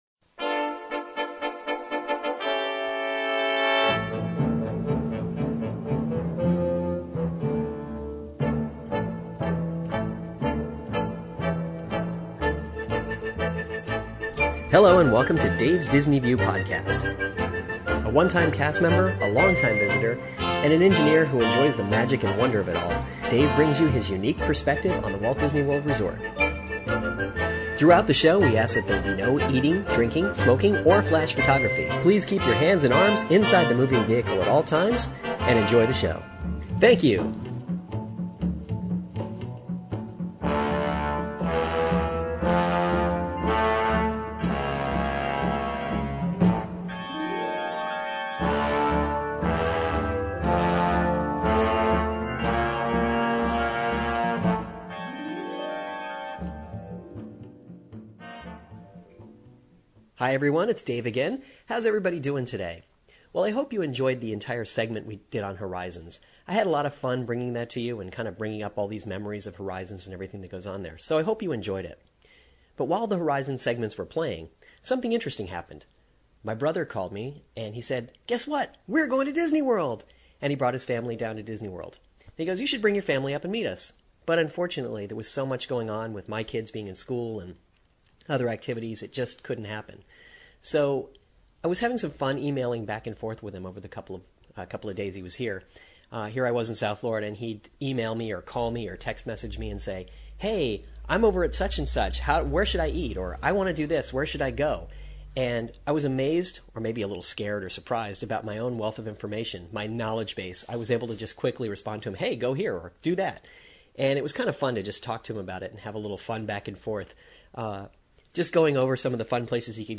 In mid-February, I took a day trip to the Magic Kingdom. I brought my mp3 recorder, so that means you get to come along and spend the day with me as I make my way around the park! Listen in as I ride some attractions, and tell some stories.